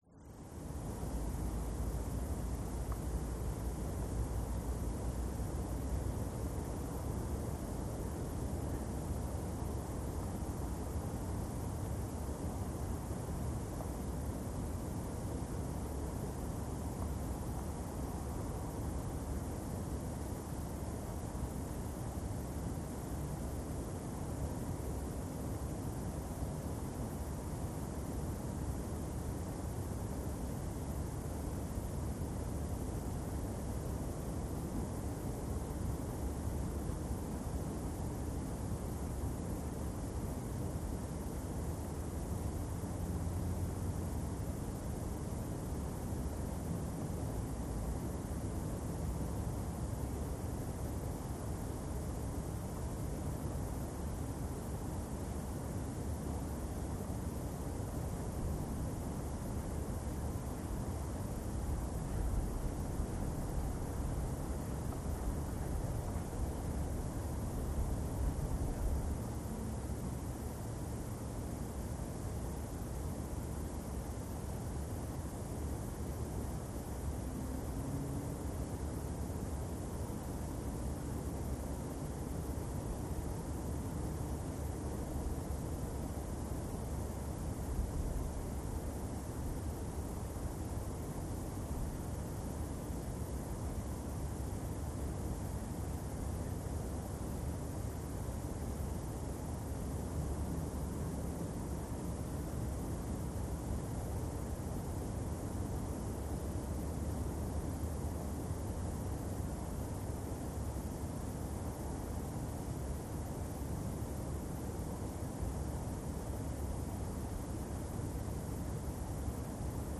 Звуки природы
Пригородный вечер с далеким шумом трафика и окружающей средой